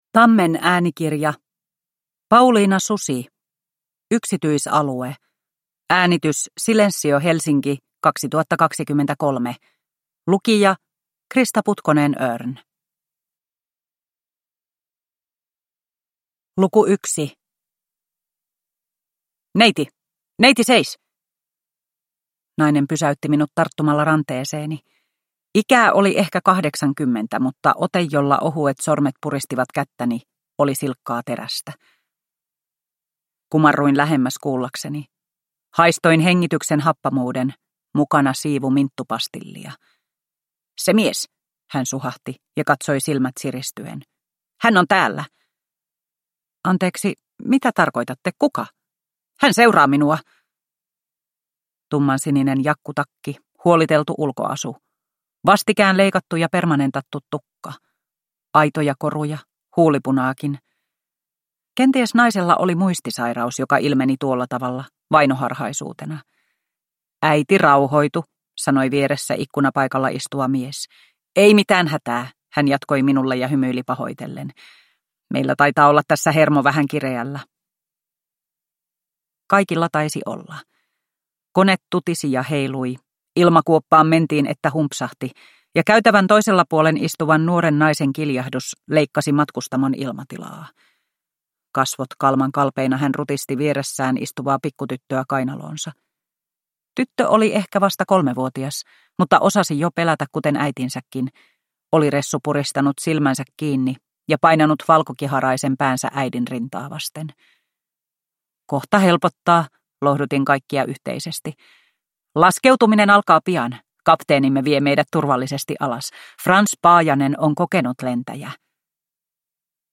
Yksityisalue – Ljudbok – Laddas ner